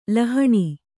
♪ lahaṇi